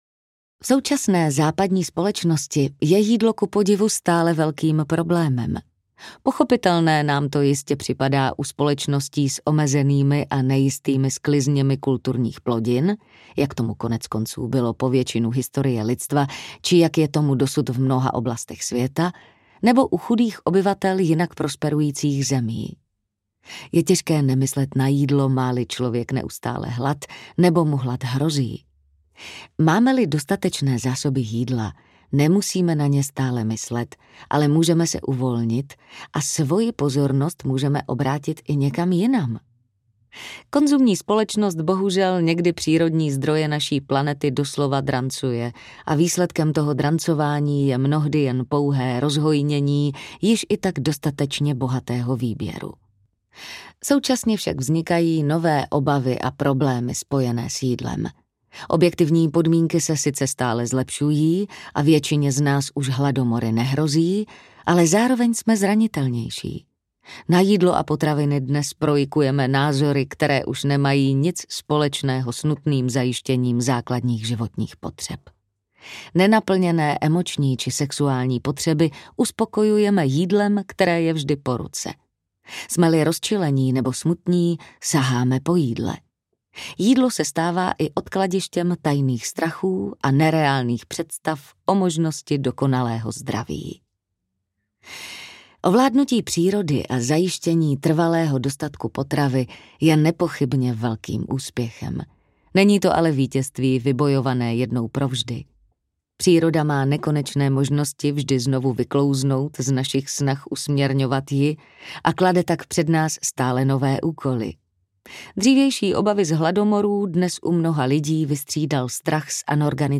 Jídlo a proměna audiokniha
Ukázka z knihy
Vyrobilo studio Soundguru.